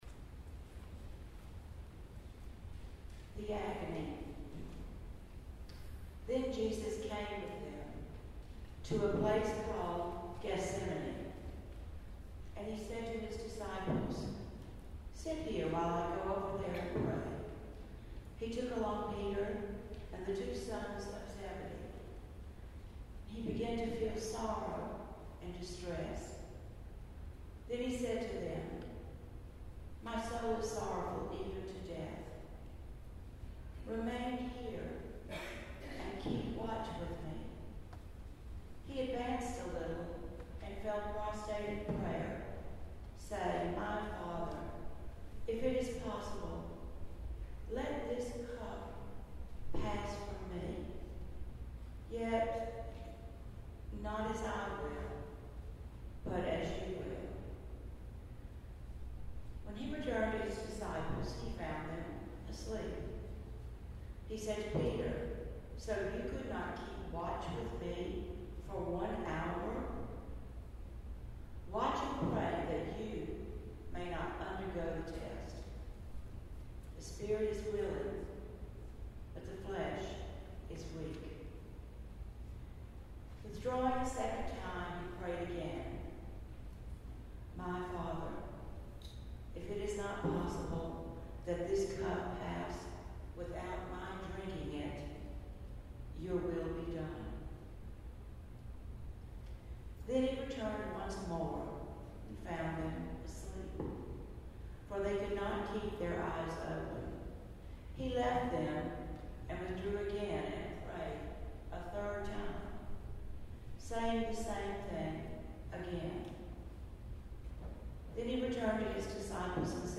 The St. William choir presented a Tenebrae Service on Palm Sunday 2015.
Reading "The Agony"